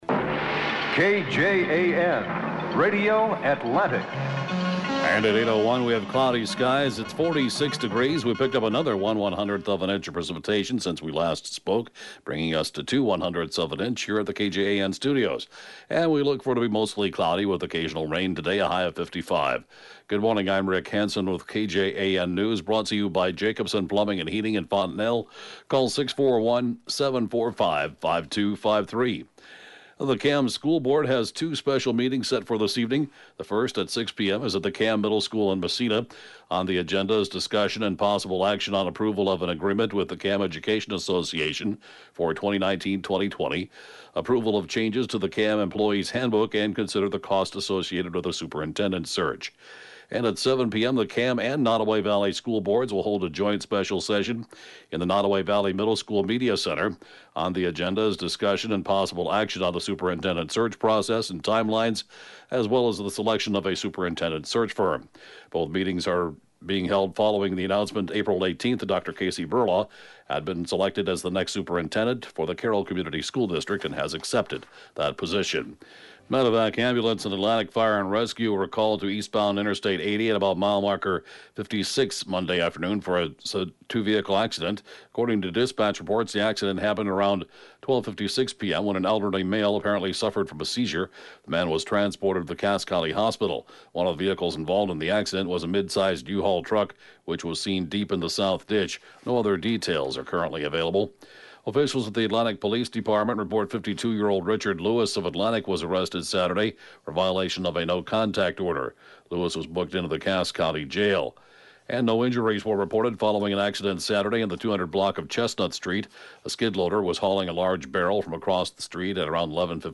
(Podcast) KJAN 8-a.m. News, 4/30/19